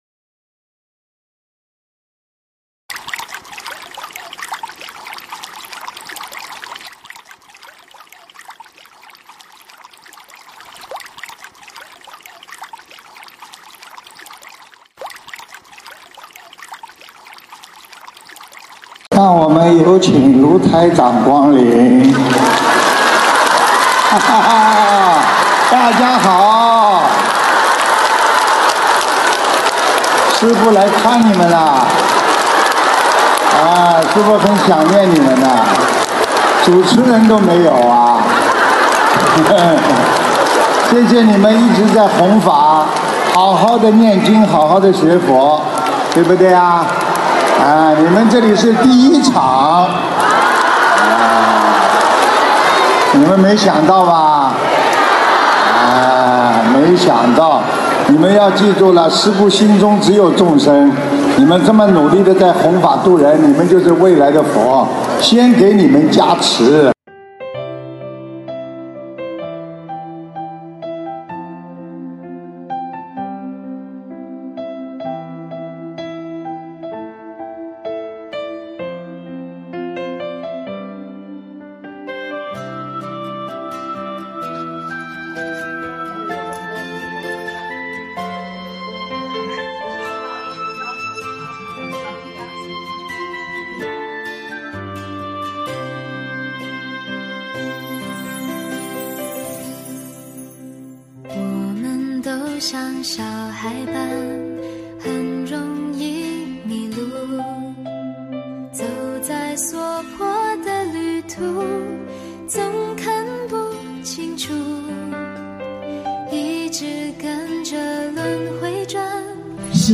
音频：德国斯图加特慈心素食交流分享会花絮！2023年06月06日（完整版)